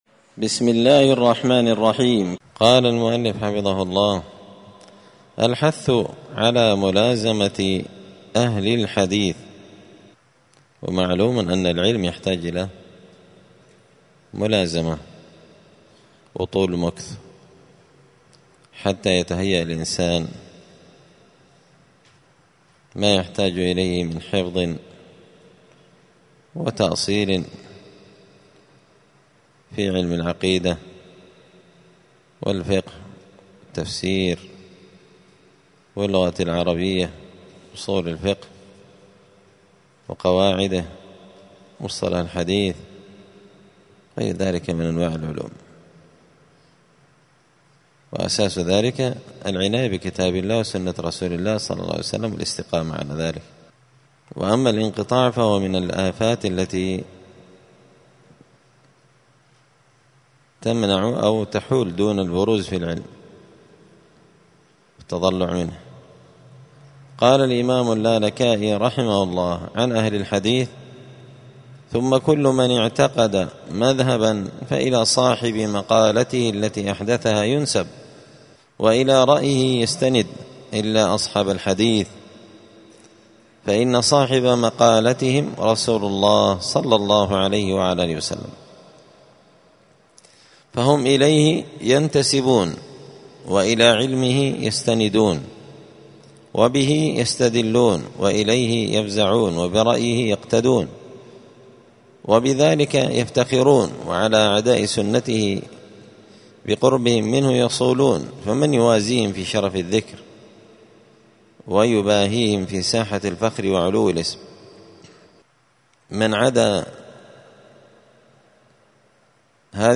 دار الحديث السلفية بمسجد الفرقان بقشن المهرة اليمن
الأثنين 6 شوال 1445 هــــ | الدروس، الفواكه الجنية من الآثار السلفية، دروس الآداب | شارك بتعليقك | 25 المشاهدات